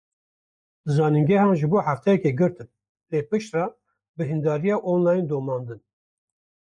/doːmɑːnˈdɪn/